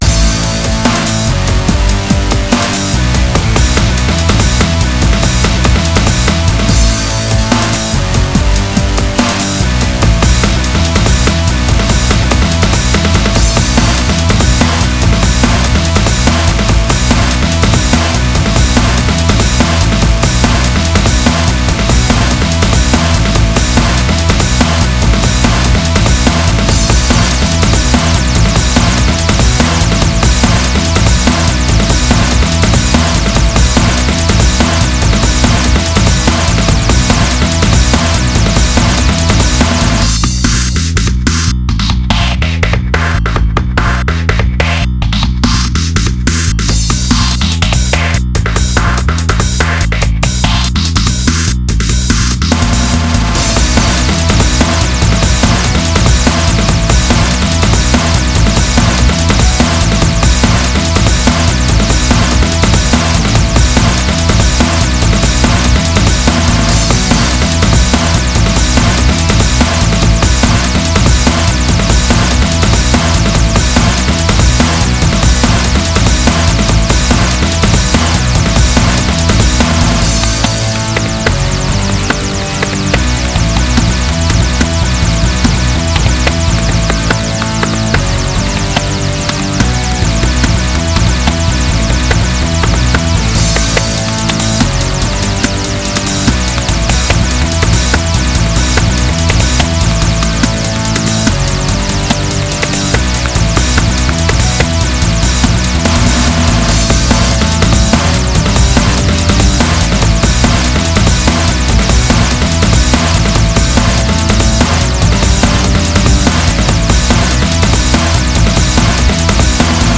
Genre：Big Beat